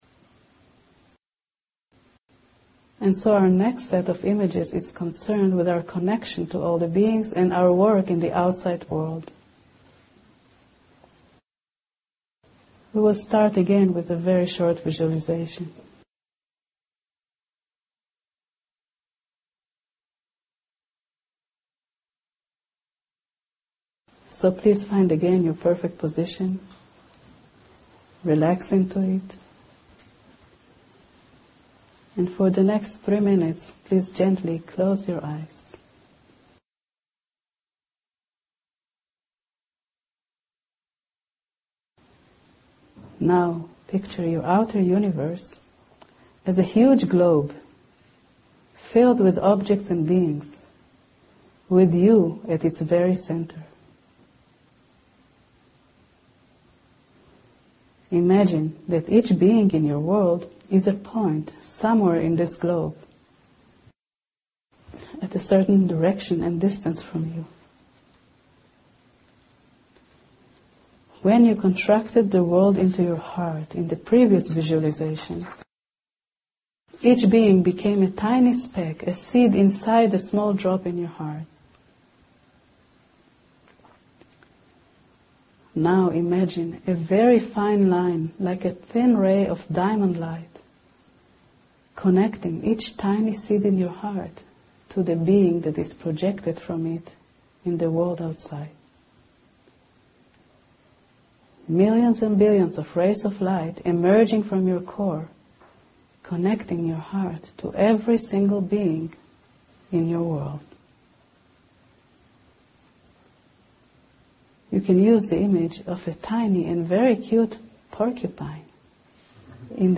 מדיטציה 3